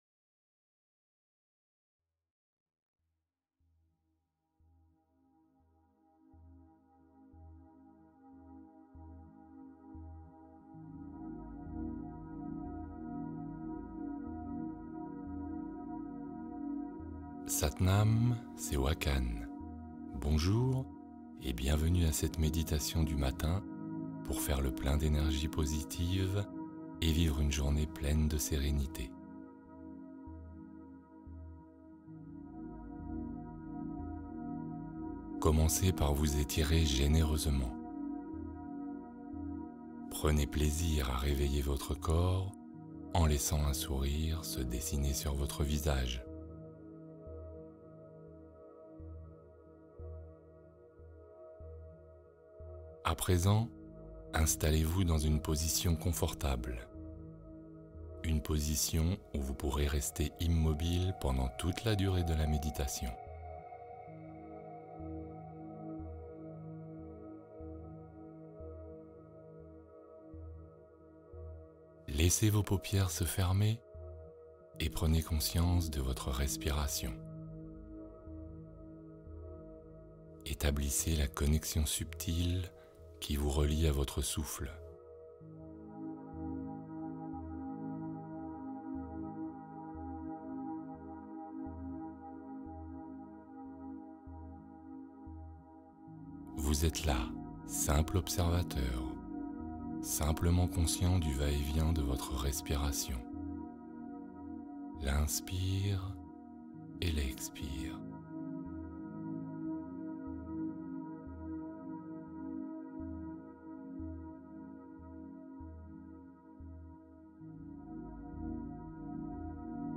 Sommeil profond : s’endormir sereinement avec une guidance apaisante